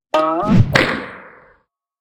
slingshot.ogg